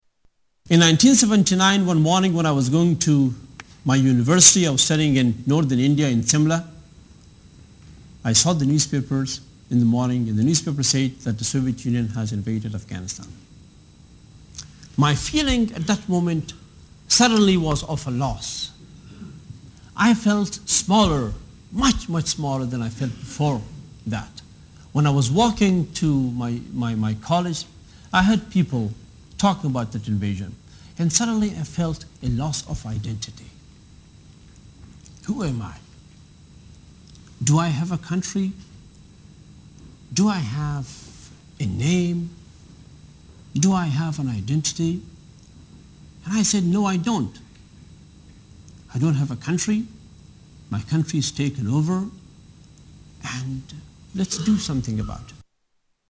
Tags: Political Hamid Karzai audio Interviews President Afghanistan Taliban